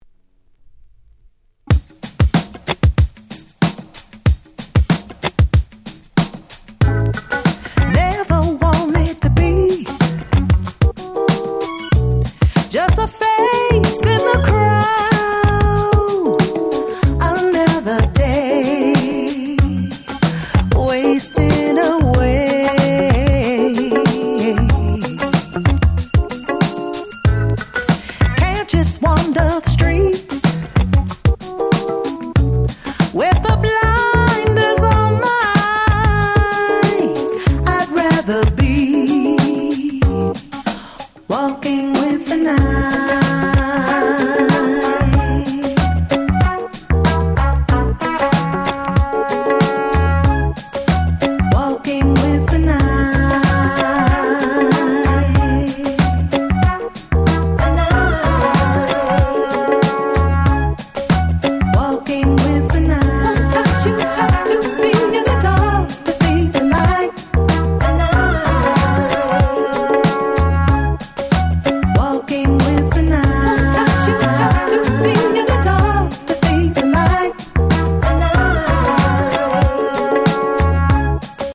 10' Super Nice Neo Soul / R&B !!